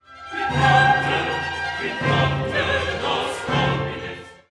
Two faster outer sections in triple time, which share several themes, flank a slow section with unrelated music in duple time.  The chorus do most of the singing in the outer sections; the soloists are prominently featured in the middle section.